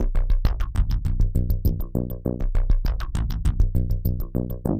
tx_synth_100_messedup_C.wav